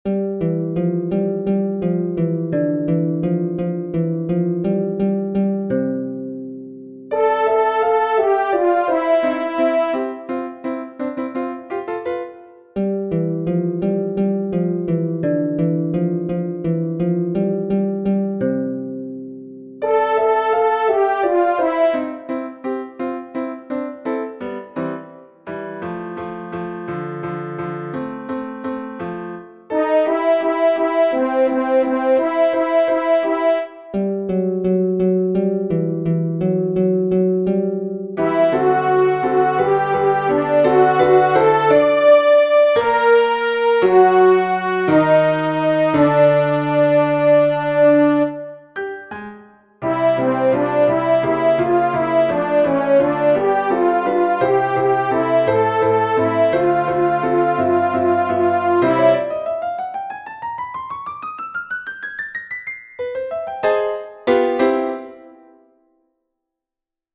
The baritone soloist sounds like a harp. The featured voice is a horn.
ALTO 1